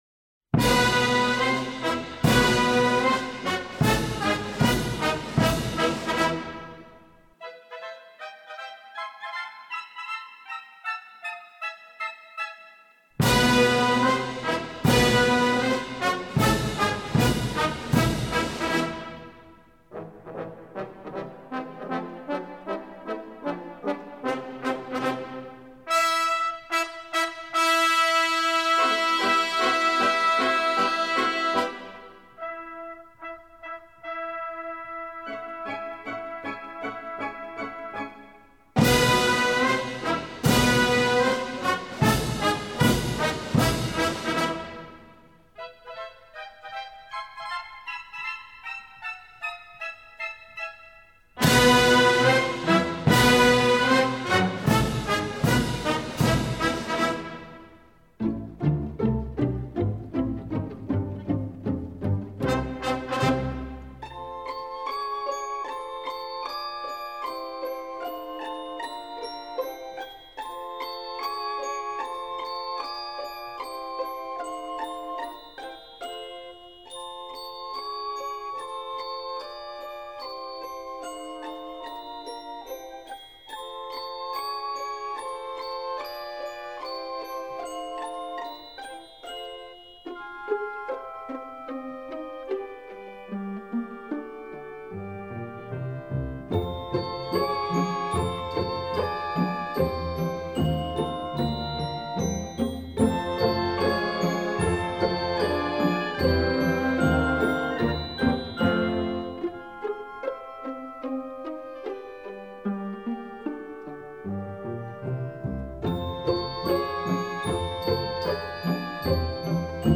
Главная / Песни для детей / Классическая музыка